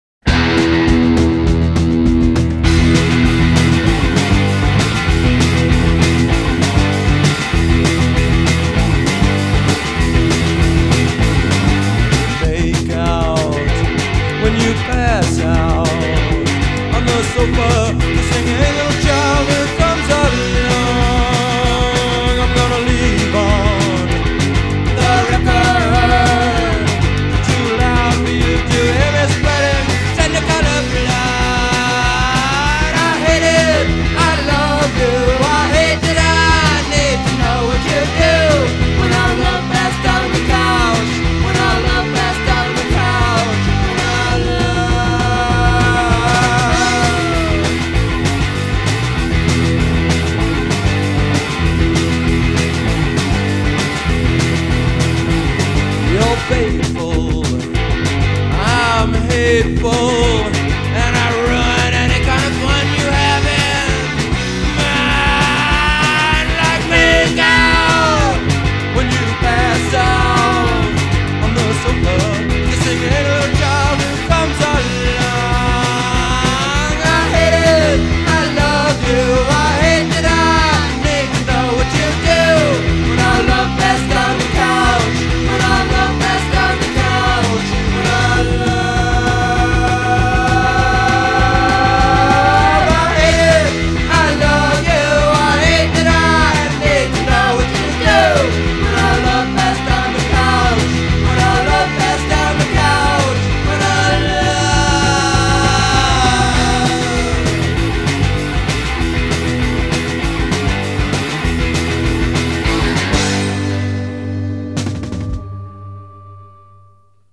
Жанр: Punk-Rock, Rockabilly, Folk-Rock, Alternative Rock